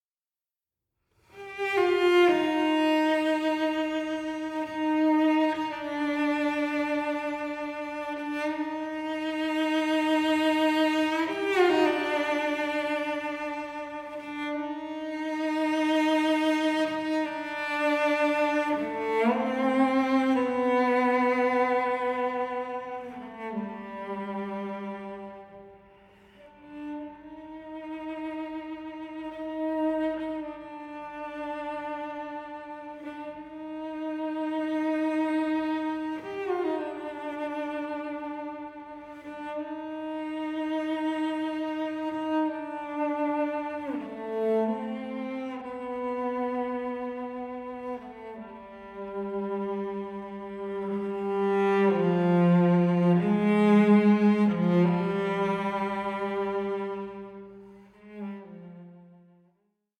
solo cello
lyrical